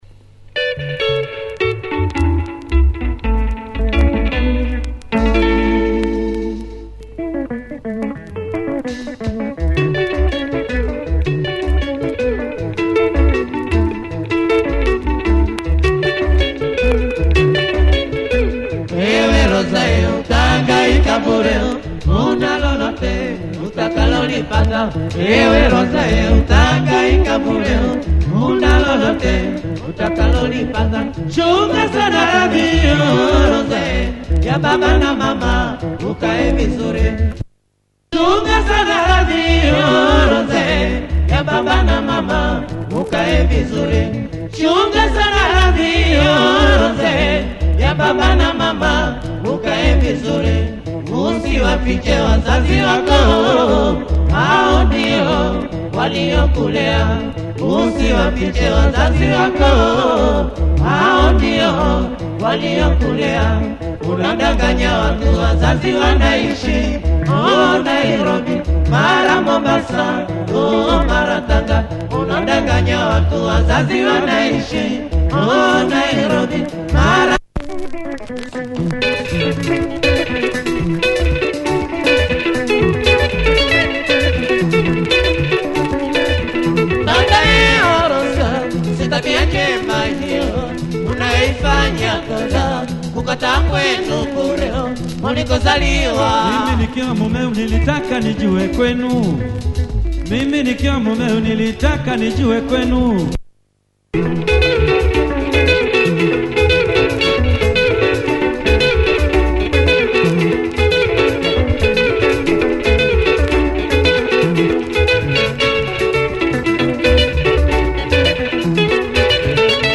Classic sounds
Changes tempo in part-2!